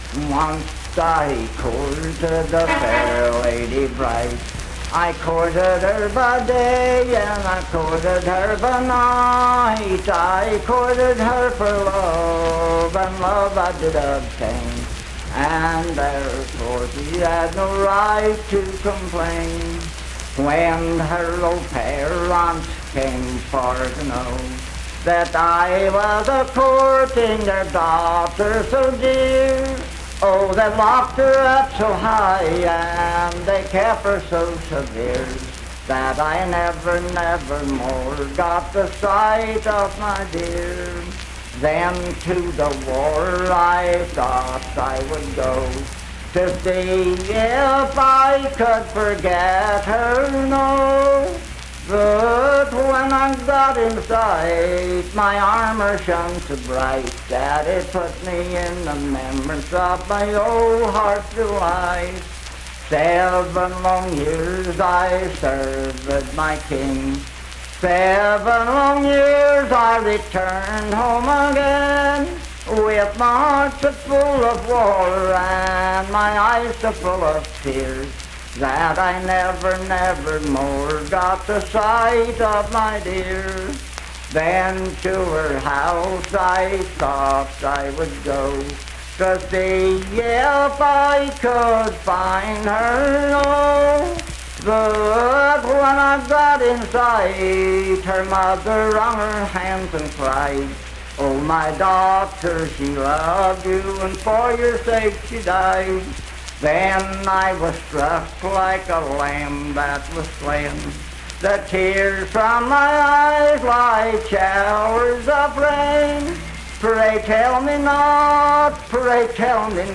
I Courted A Lady Bright - West Virginia Folk Music | WVU Libraries
Unaccompanied vocal music performance
Voice (sung)